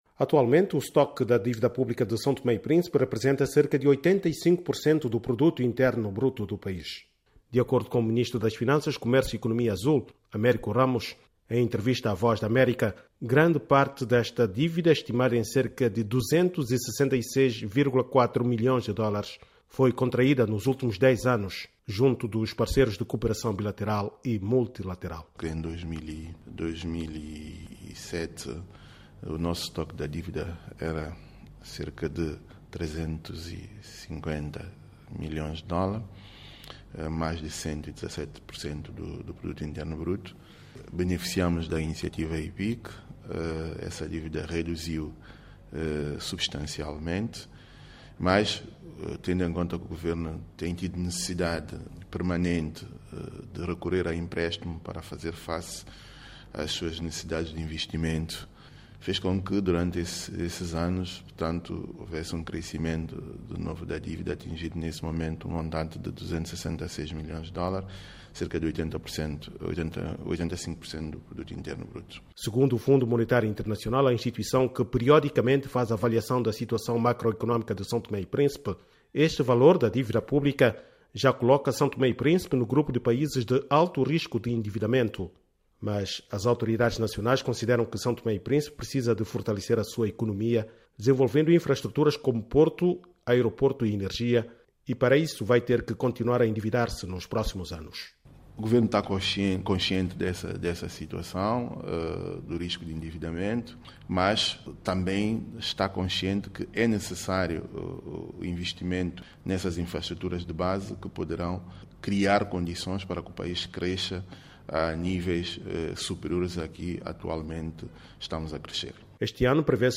O ministro das Finanças, comércio e Economia Azul, Américo Ramos, fala com a VOA e explica o endividamento contraído nos últimos 10 anos.